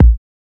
OZ - Kick 3.wav